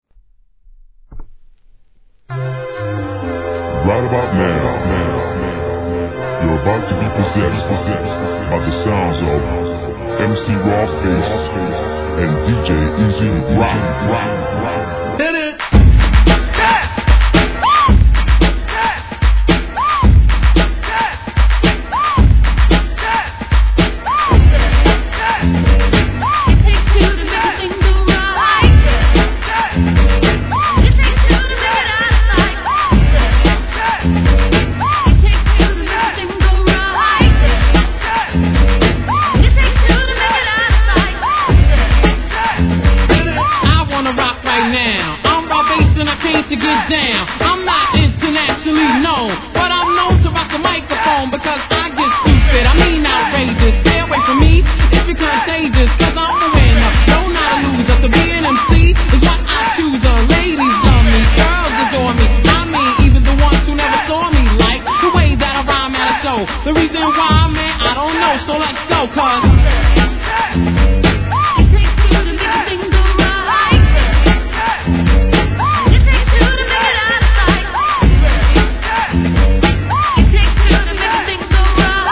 HIP HOP/R&B
永遠のPARTYトラックのネタクラシックでしょう!